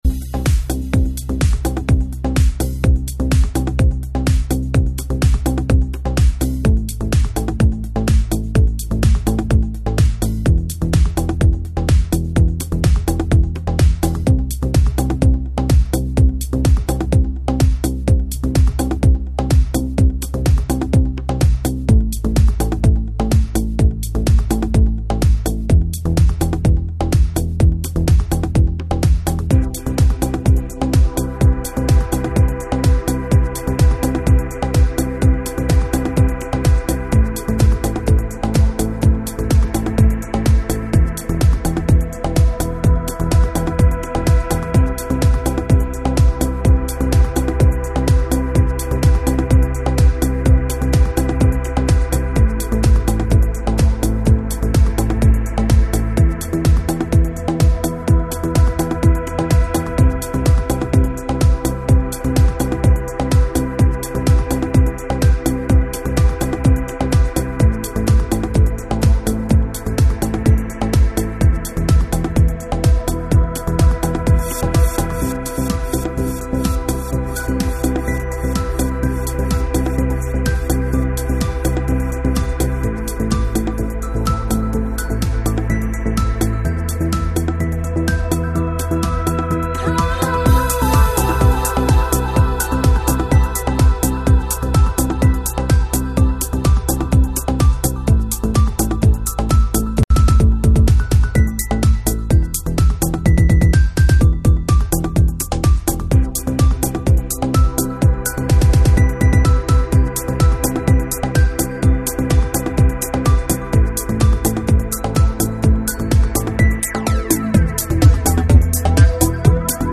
Стиль: Только Trance